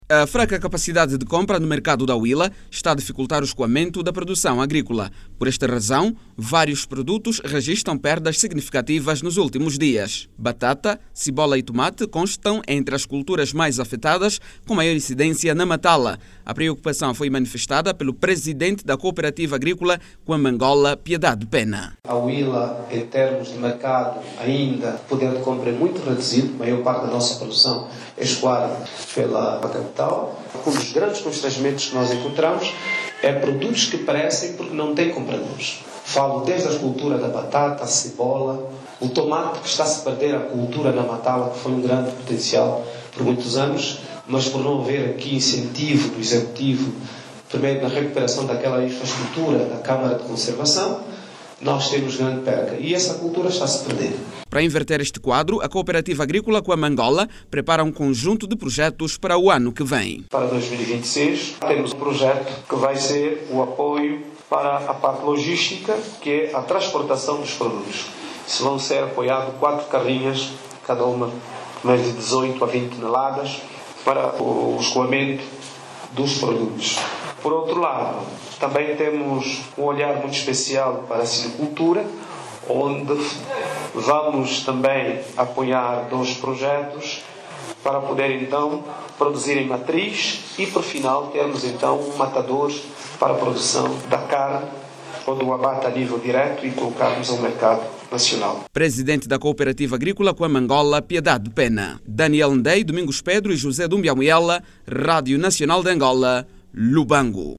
A informação foi avançada pelos presidentes de algumas cooperativas, que falam de uma nova estratégia para o escoamento dos produtos.